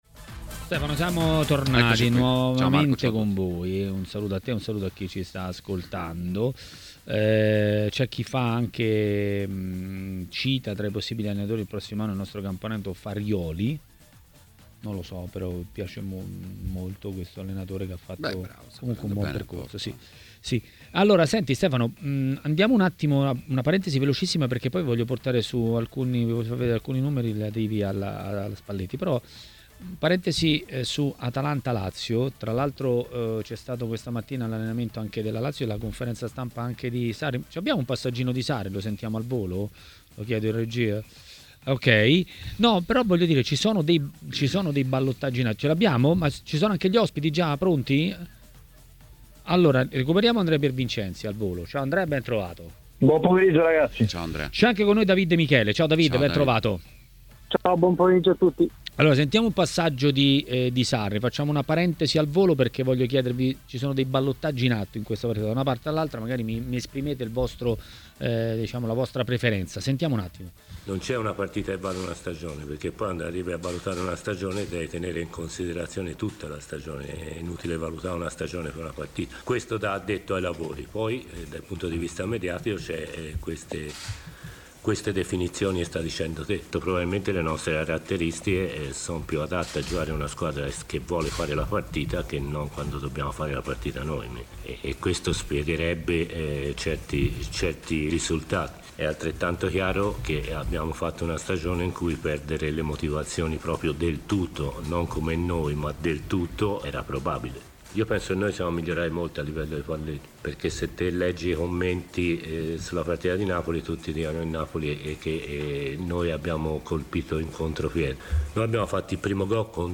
Ospite di Maracanà, nel pomeriggio di TMW Radio, è stato l'ex calciatore e tecnico David Di Michele.